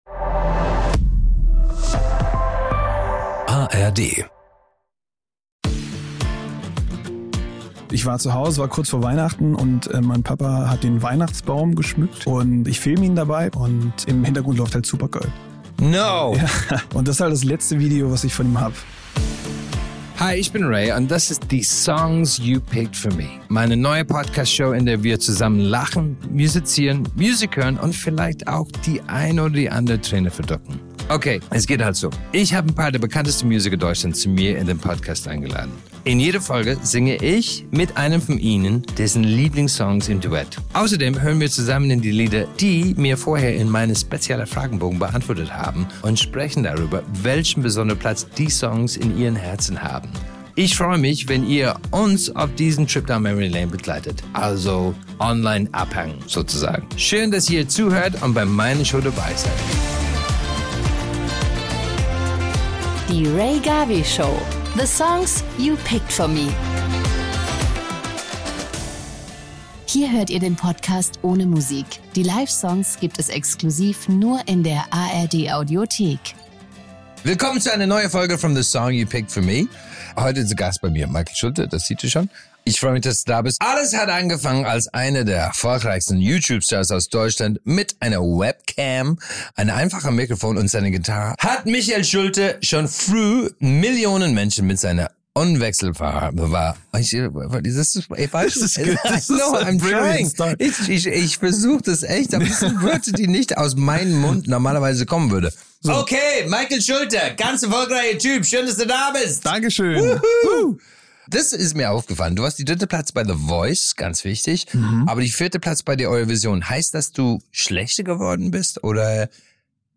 In dieser Folge begrüßt Rea Garvey seinen langjährigen Freund und Streaming-Milliardär Michael Schulte. Die beiden blicken zurück auf Michaels allerersten Live-Auftritt, der sogar mit Rea war.